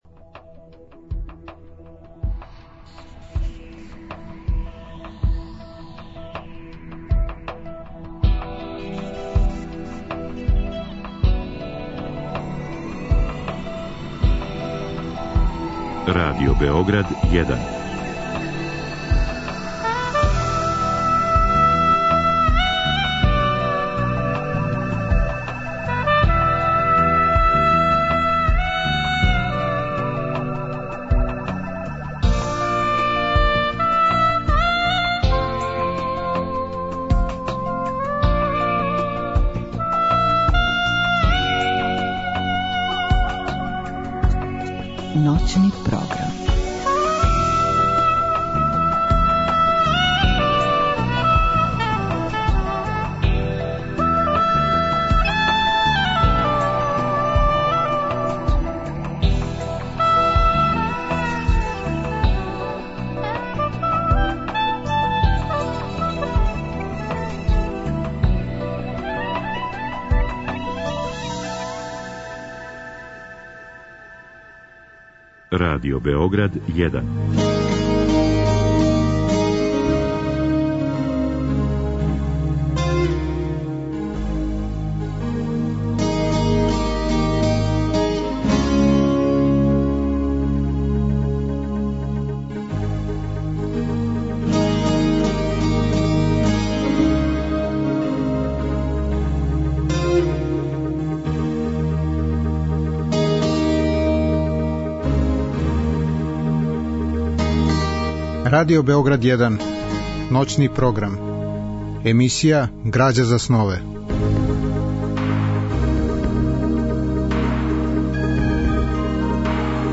Разговор и добра музика требало би да кроз ову емисију и сами постану грађа за снове.
У другом делу емисије, од два до четири часа ујутро, као својеврсну личну антологију, слушаћемо стихове које је одабрао и које интерпретира глумац Љубивоје Тадић.